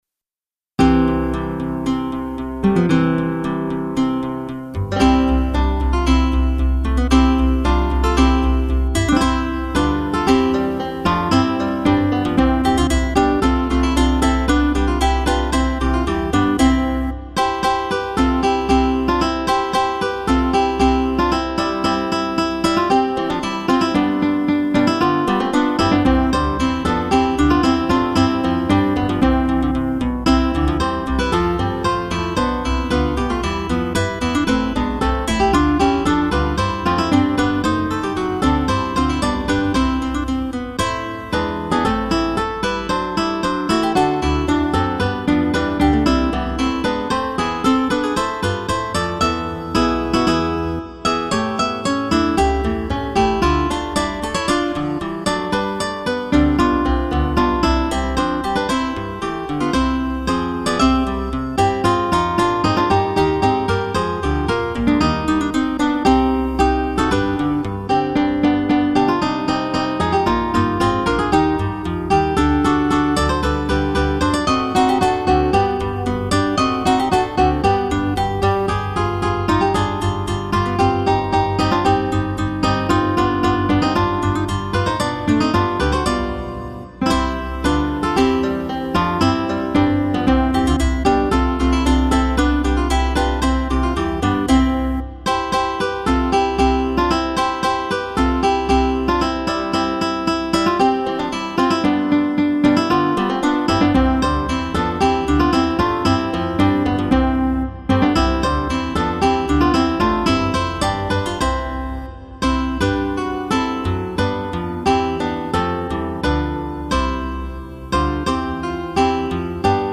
Weihnachtskonzert für 4 Gitarren